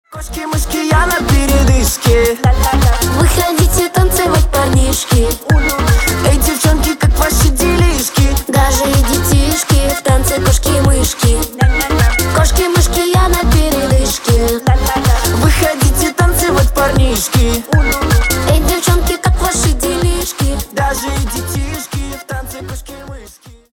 поп
детские